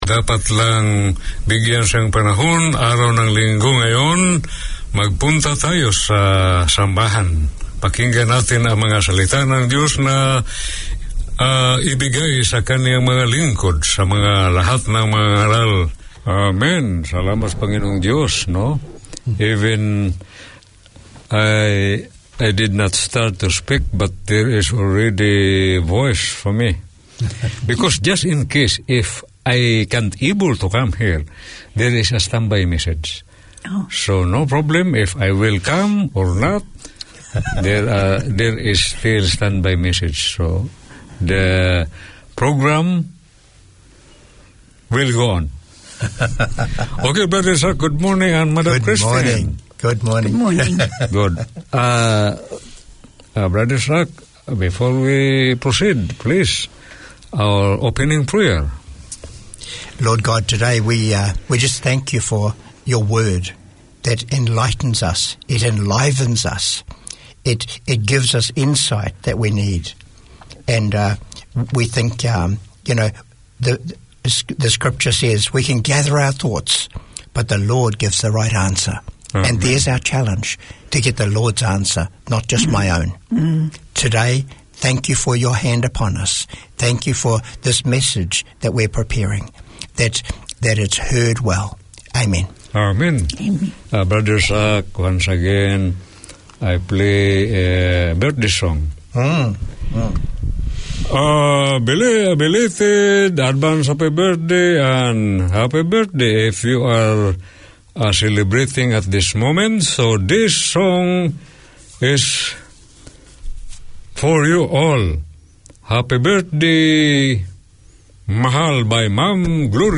Tune in for scripture and gospel music, as well as occasional guest speakers who share their passion and faith.